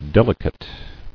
[del·i·cate]